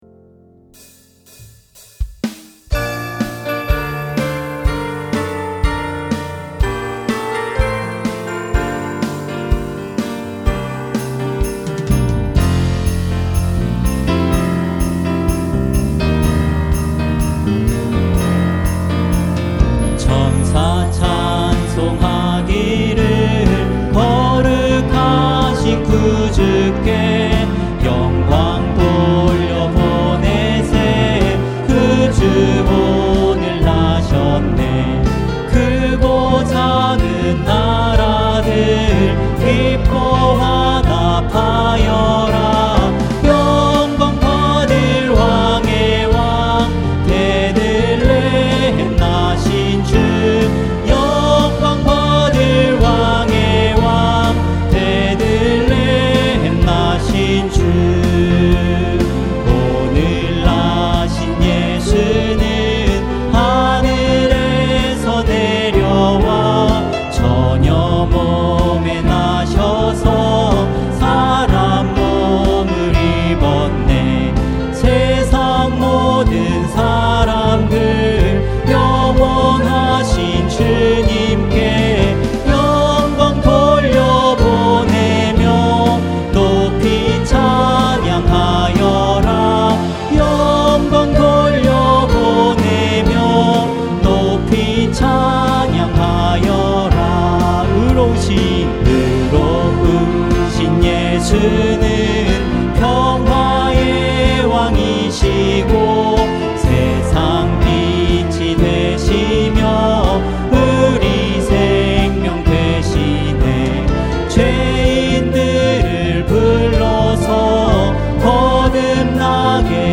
찬양 음악